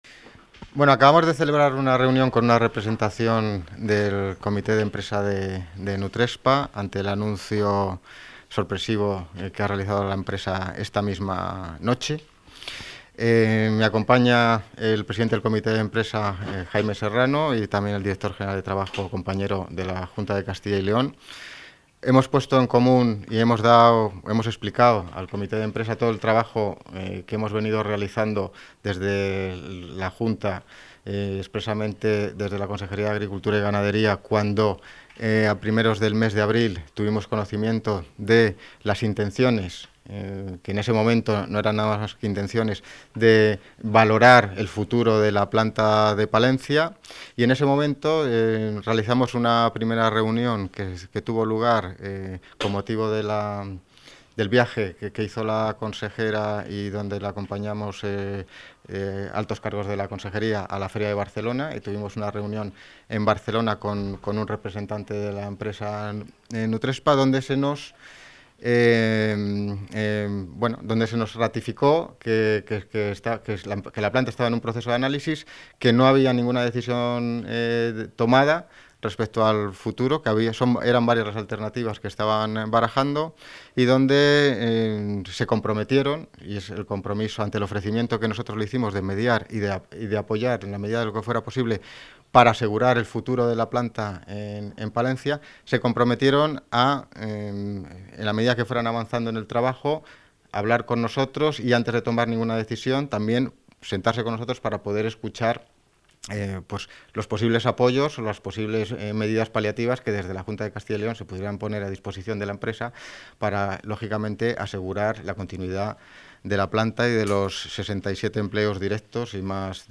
Audio director general Ind. Agrarias y Moder. Explotaciones, Jorge Morro.